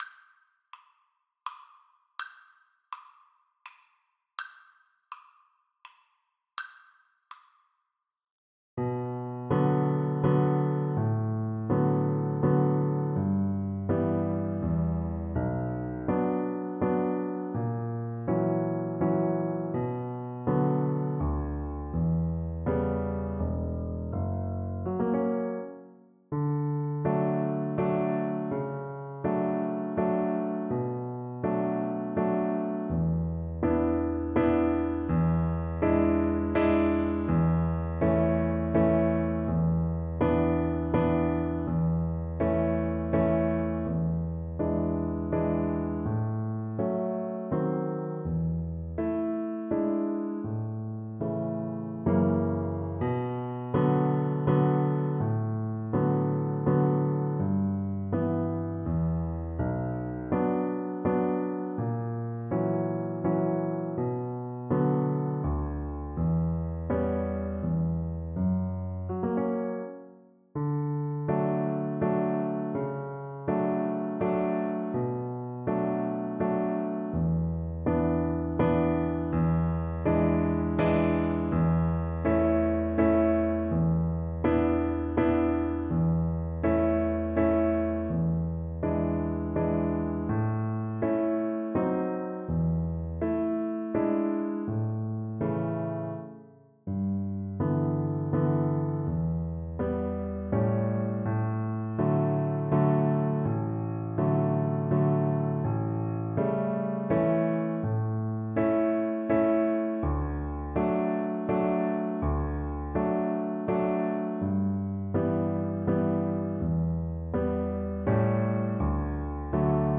Classical Chopin, Frédéric Waltz Op. 70 No. 2 Viola version
Play (or use space bar on your keyboard) Pause Music Playalong - Piano Accompaniment Playalong Band Accompaniment not yet available transpose reset tempo print settings full screen
Viola
D major (Sounding Pitch) (View more D major Music for Viola )
Tempo giusto = 144
3/4 (View more 3/4 Music)
Classical (View more Classical Viola Music)